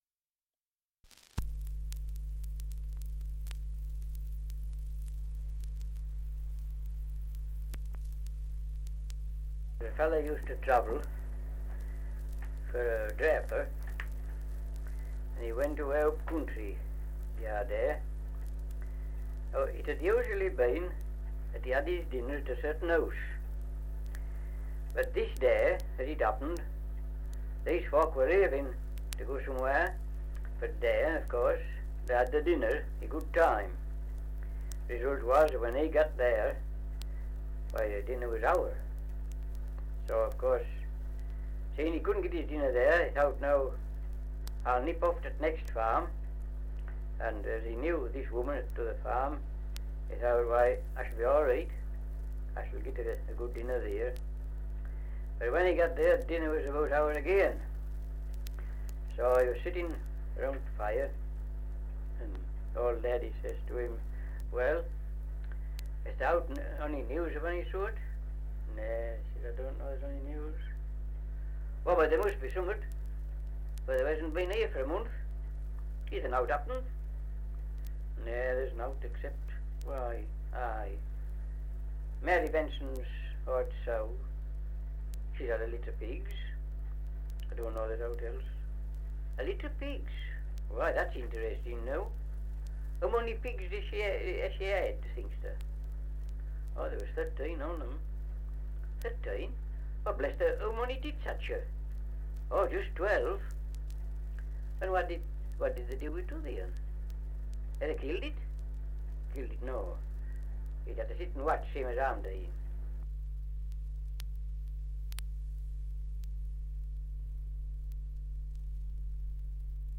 Survey of English Dialects recording in Bedale, Yorkshire
78 r.p.m., cellulose nitrate on aluminium